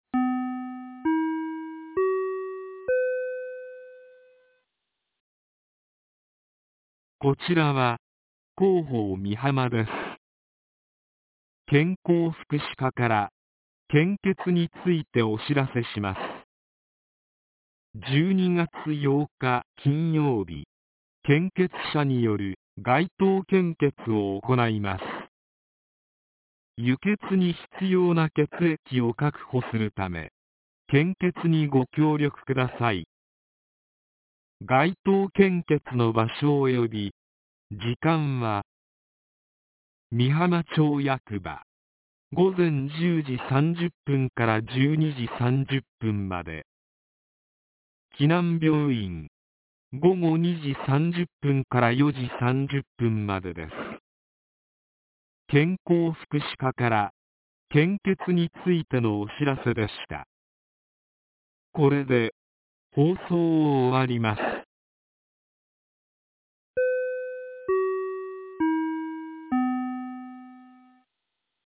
■防災行政無線情報■
放送内容は下記の通りです。